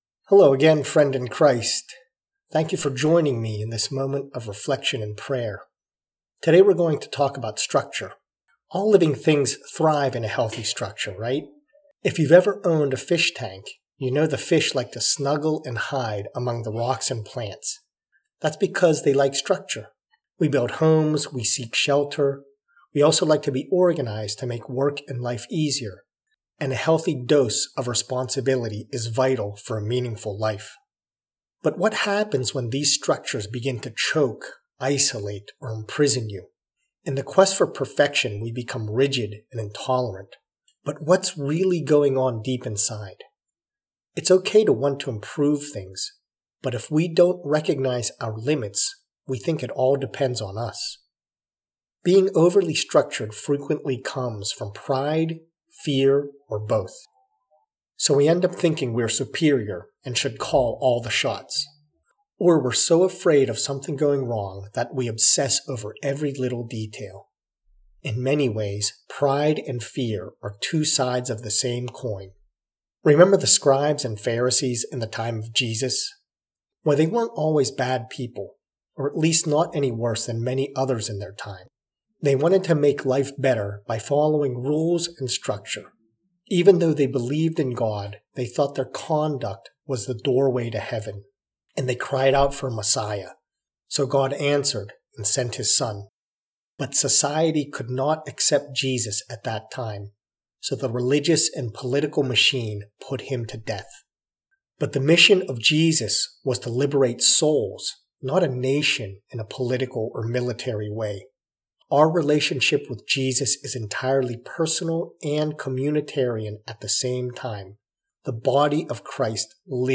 prayer-to-be-free-of-too-much-structure.mp3